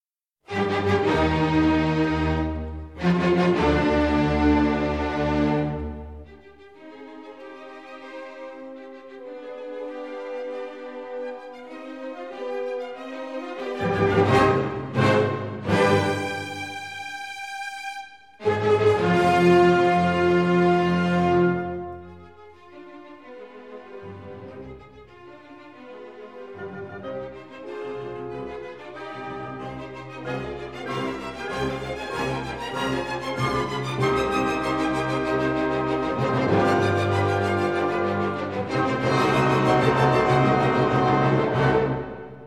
• Mode: Stereo
Ao nível da qualidade subjectiva não foram detectadas grandes diferenças entre os vários formatos.
De seguida encontram-se diversos media players com amostras dos primeiros 42 segundos da música em causa, nos diferentes formatos, de forma ser possivel avaliar a qualidade subjectiva do som.